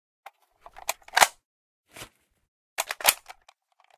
svt40_reload.ogg